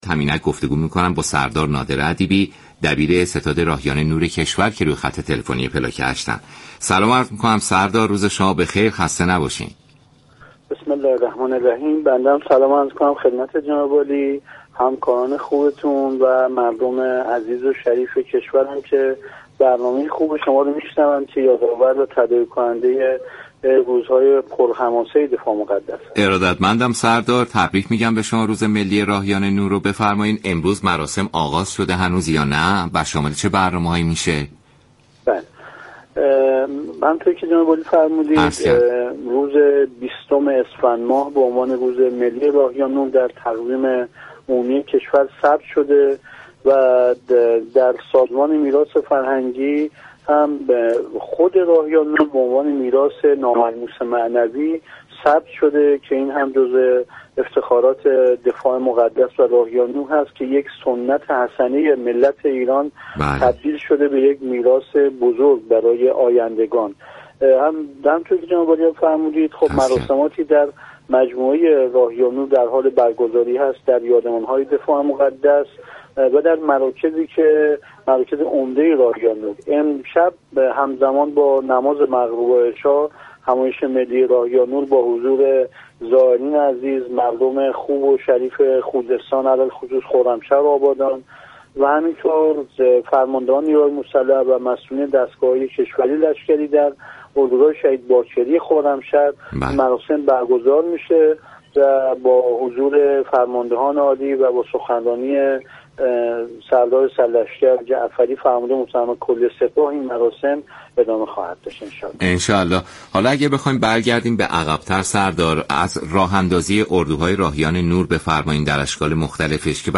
سردار نادر ادیبی دبیر ستاد راهیان نور كشور در برنامه پلاك هشت رادیو ایران درباره برنامه های راهیان نور امسال گفت : در حال حاضر هزاران و میلیون ها نفر از مناطق عملیاتی راهیان نور بازدید می كنند.